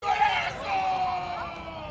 Play, download and share GOLAZOOOOOOOOOOOOOOOOOOOO original sound button!!!!
golazoooooooooooooooooooo.mp3